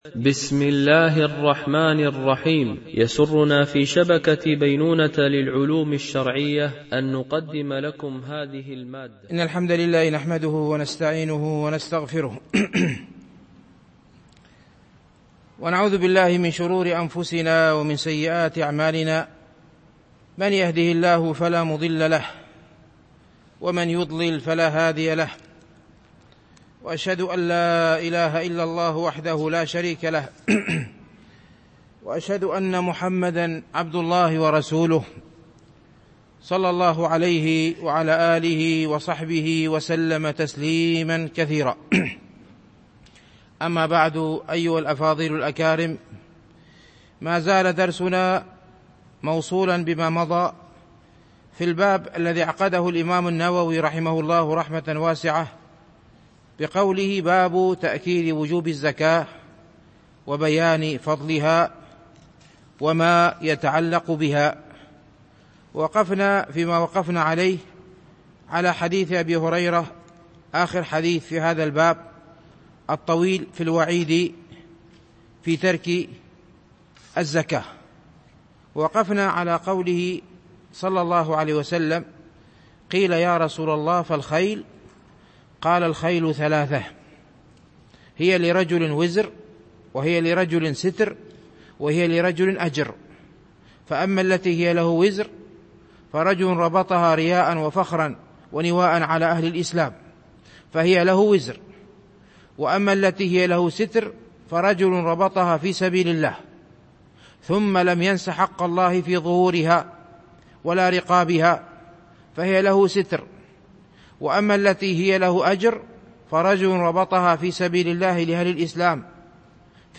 شرح رياض الصالحين – الدرس 316 ( الحديث 1222 )